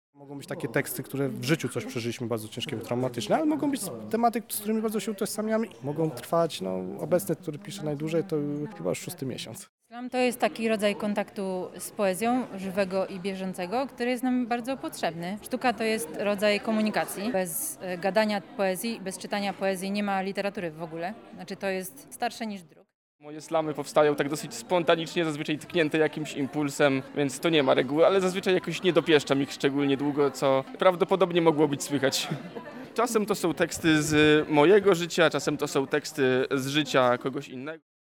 Rozmawialiśmy z uczestnikami wydarzenia.